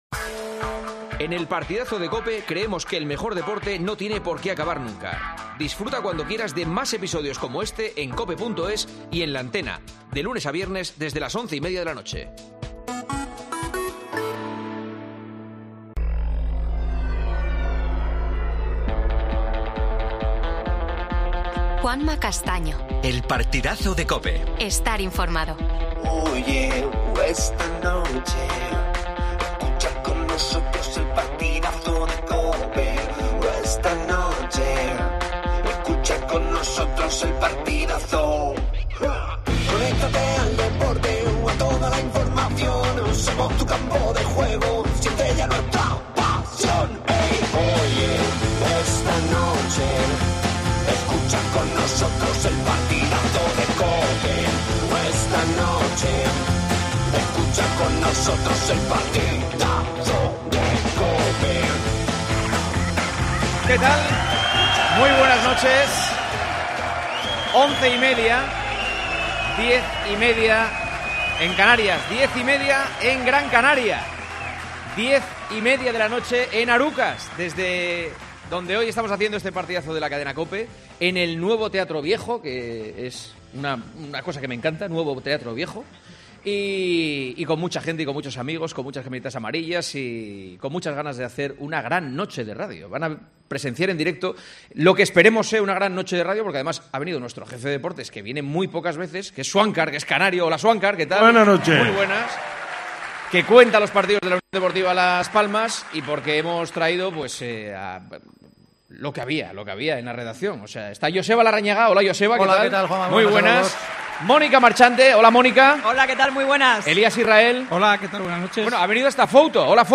AUDIO: Programa especial desde Las Palmas. Rafa Nadal renuncia a jugar el torneo de Montecarlo.